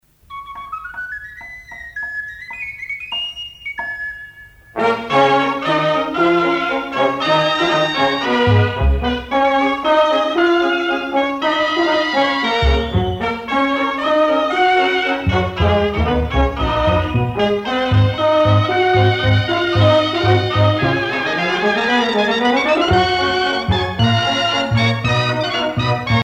danse : sardane